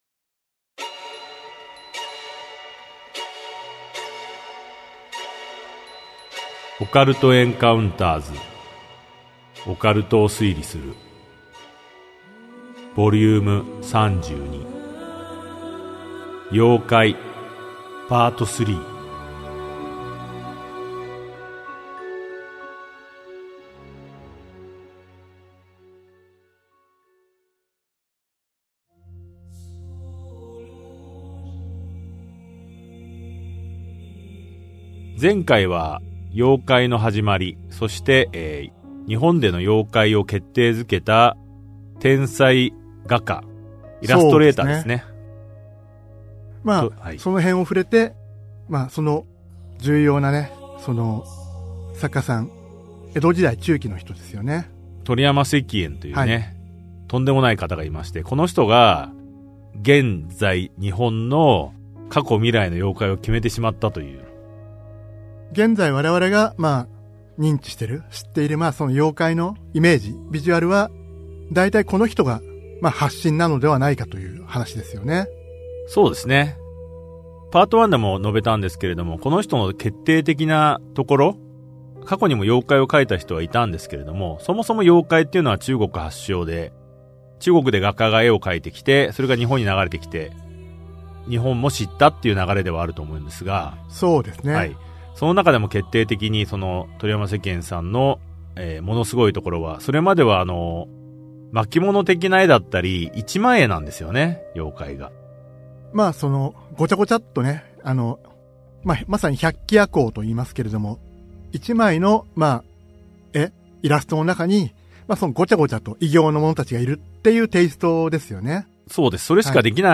[オーディオブック] オカルト・エンカウンターズ オカルトを推理する Vol.32 妖怪 3
オカルト・エンカウンターズの二人が伝承と文献を紐解き、伝説の裏側を推理する──。